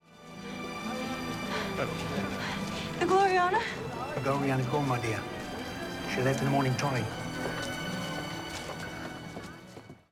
Authoritative, confident and professional